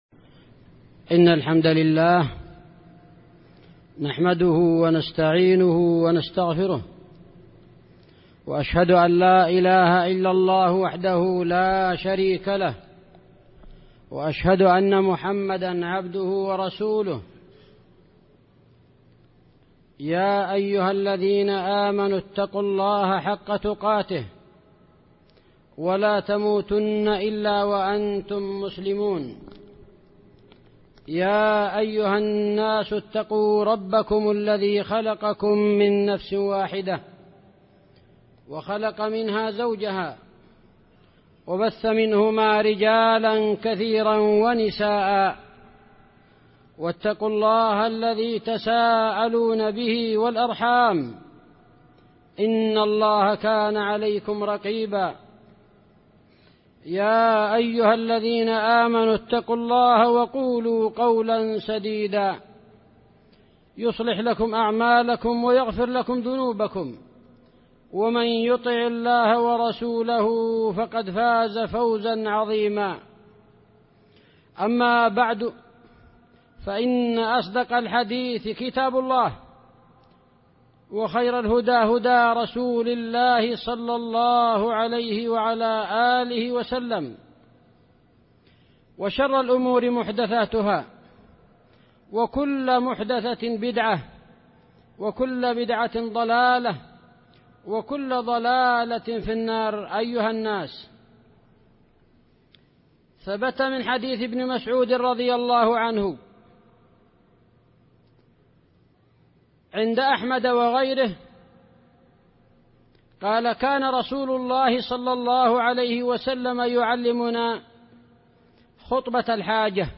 خطبة جمعة بعنوان: الترغيب في فقه اسم الله الرقيب سجلت في 27 جمادى الأولى 1438هـ